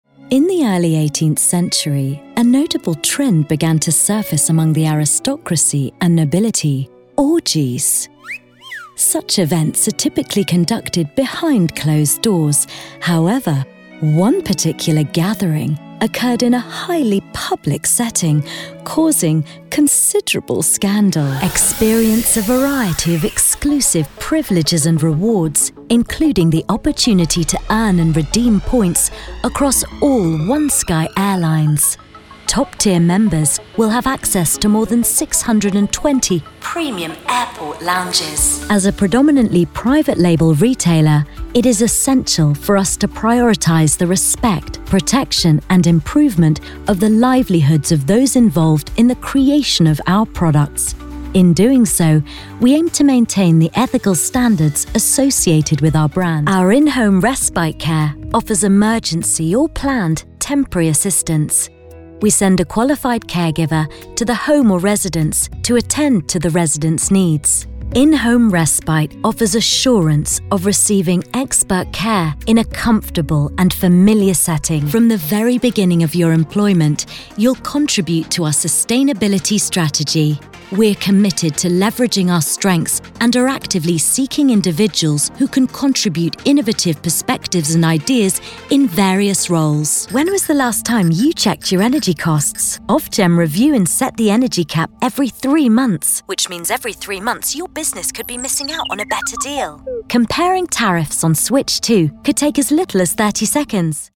Professional British Voiceover Artist & live event Voice of God with a clear, bright and reassuring voice
Sprechprobe: Industrie (Muttersprache):
She has a naturally modern RP accent and plays in the Teens - 30s age range.